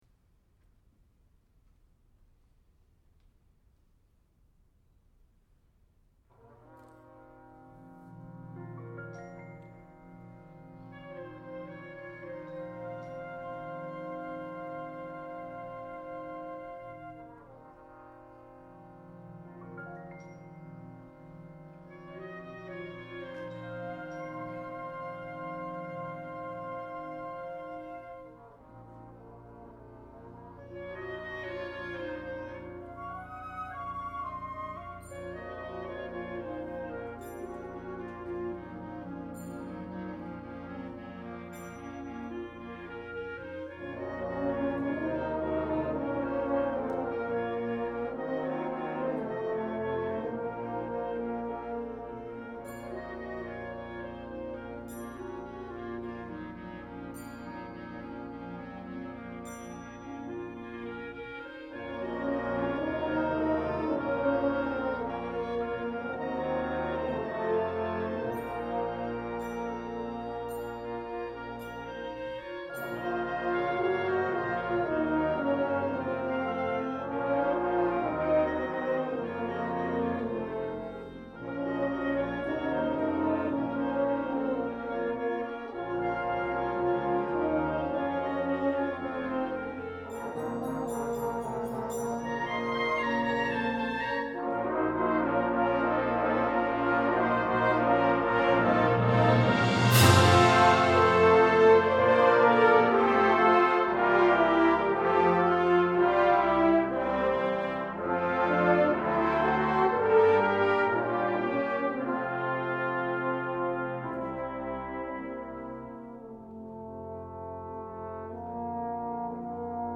Genre: Band